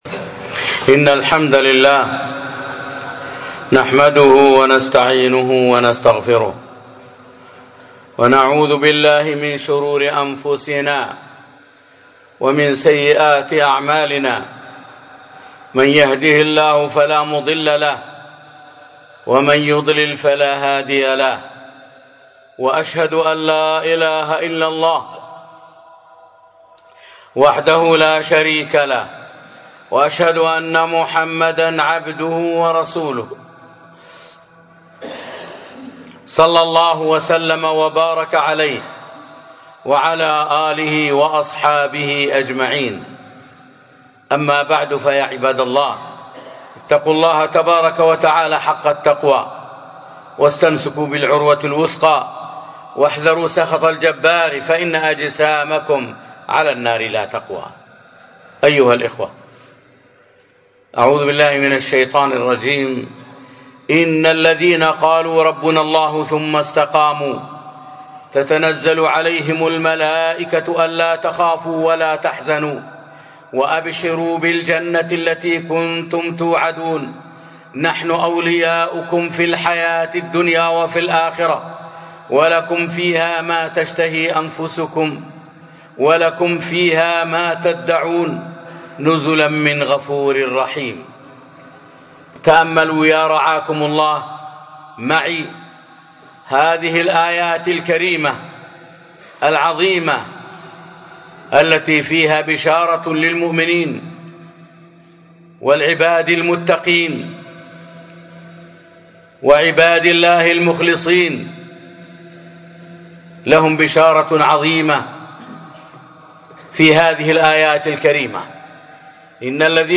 خطبة - ن الذين قالوا ربنا الله ثم استقاموا...